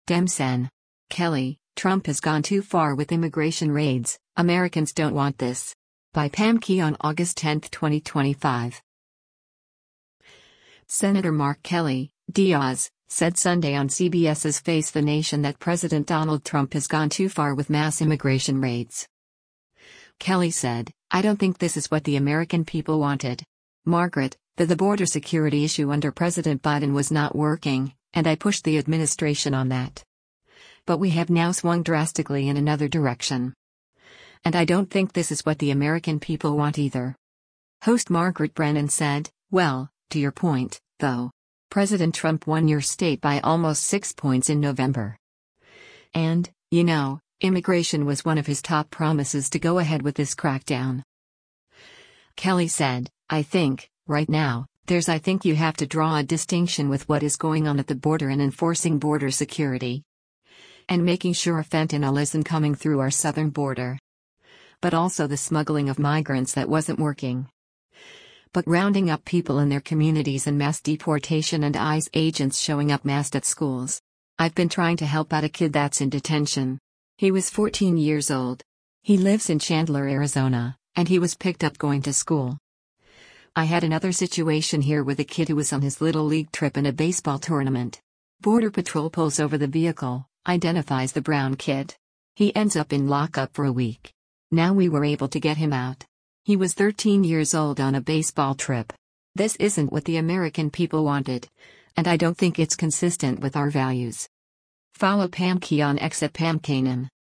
Senator Mark Kelly (D-AZ) said Sunday on CBS’s “Face the Nation” that President Donald Trump has gone too far with mass immigration raids.”